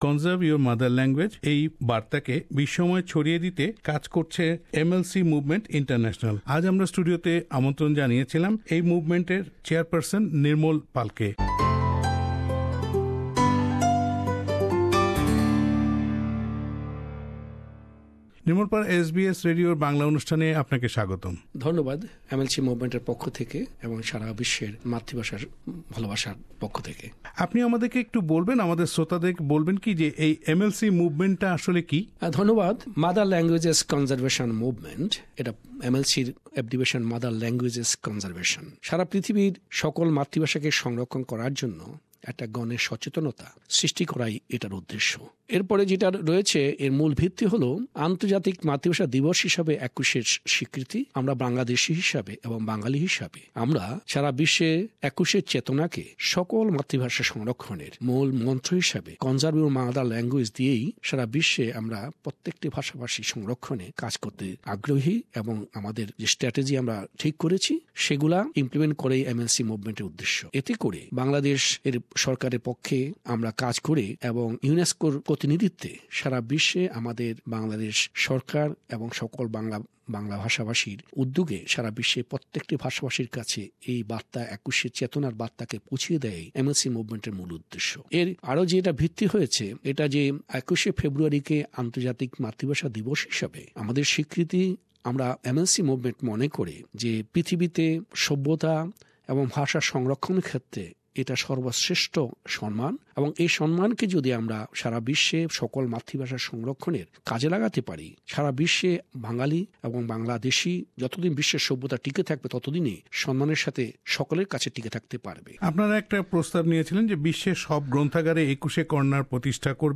MLC movement : Interview